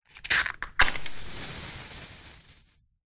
Звуки спички
Достать спичку из коробка чиркнуть о бок зажечь короткое пламя